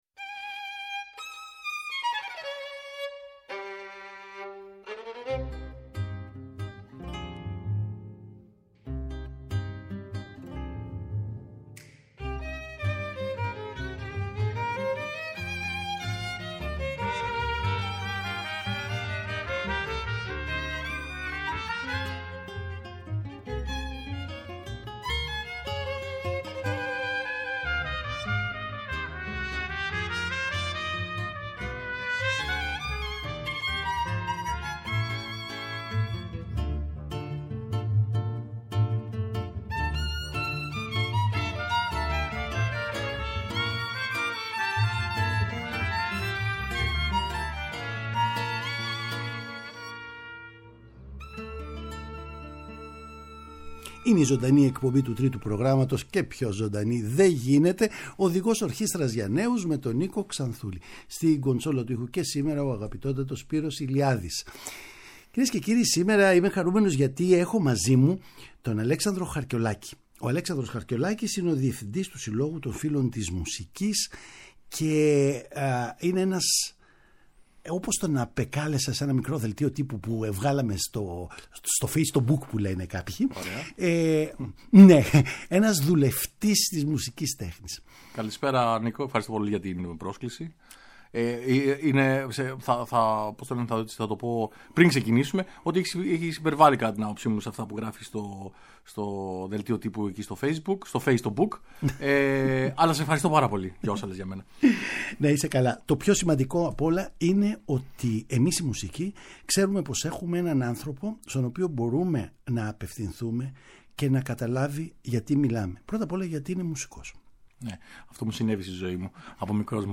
Είναι χαρά μας να τον έχουμε στην εκπομπή μας για να μοιραστούμε σκέψεις, ιδέες και μουσικές.
Παραγωγή-Παρουσίαση: Νίκος Ξανθούλης